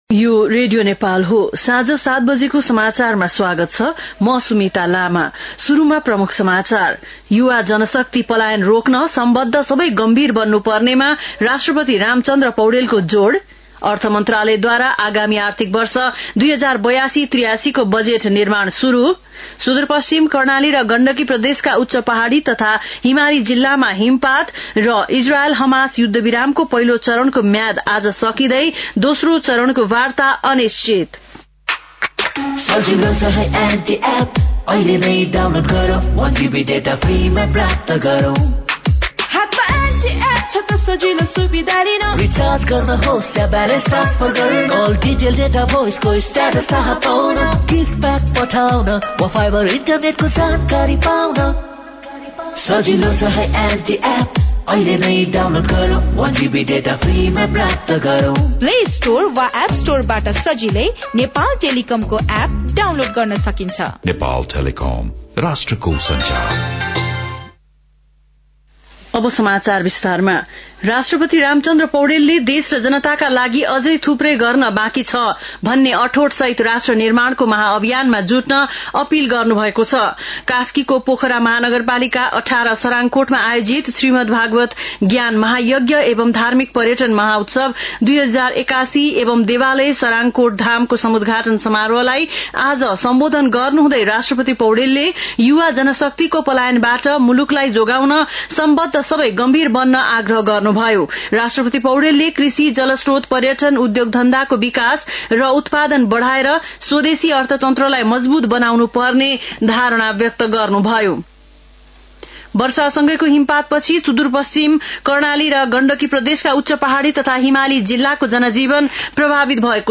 बेलुकी ७ बजेको नेपाली समाचार : १८ फागुन , २०८१